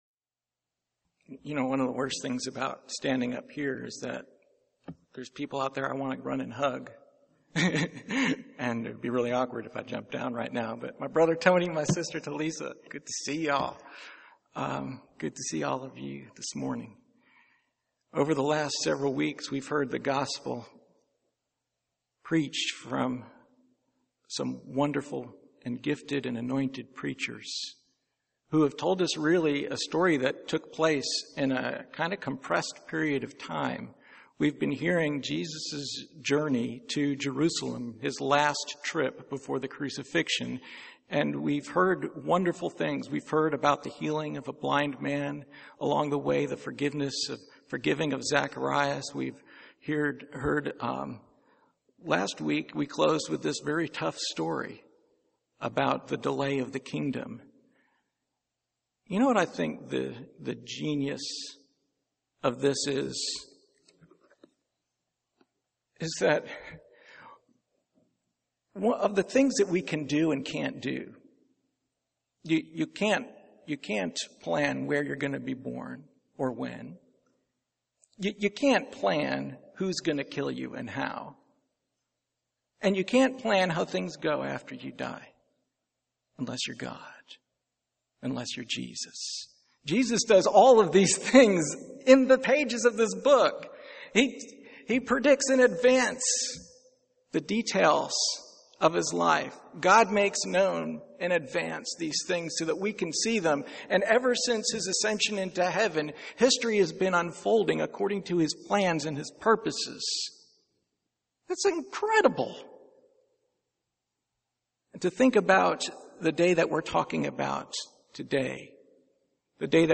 Passage: 1 Corinthians 14:26-36 Service Type: Sunday Morning